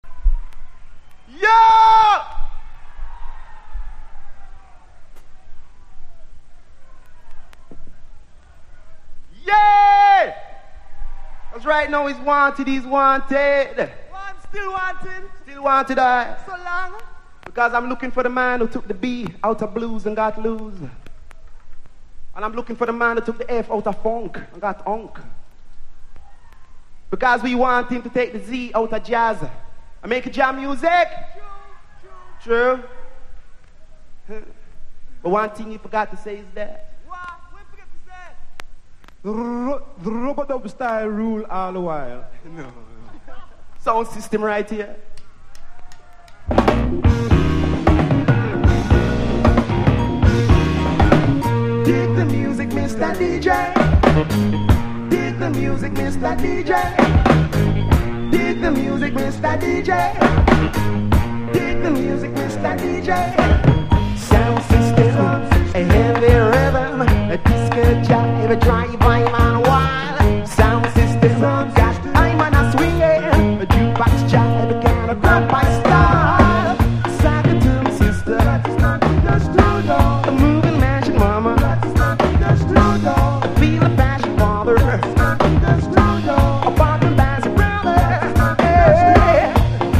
81年にジャマイカで動員のべ6万人という大規模で行われた、ボブ･マーリーの追悼イベントの模様を収録したライブ盤。